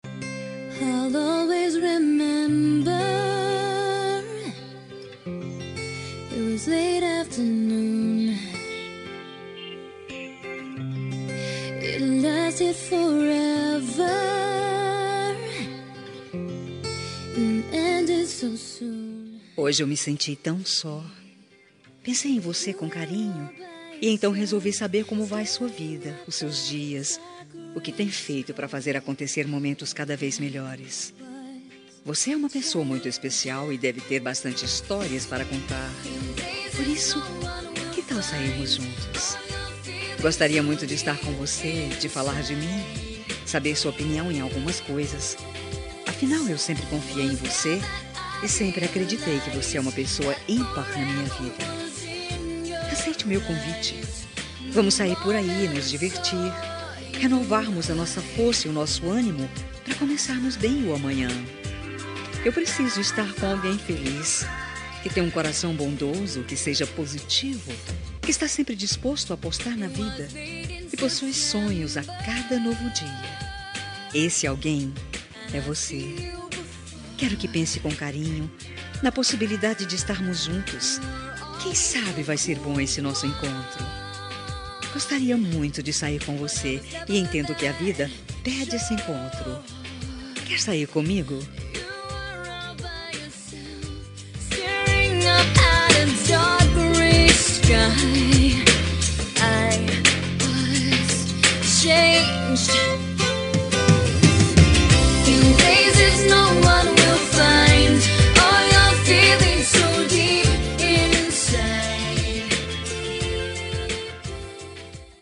Telemensagem de Pedido – Voz Feminina – Cód: 4168 – Quer sair
4168-quer-sair-comigo-fem.m4a